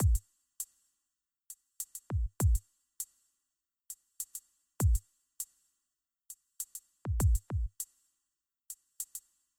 «Хэты» затем обработал эквалайзером и добавил им ревербератор.
Фигуры бас-бочки и «хэтов» слил вместе.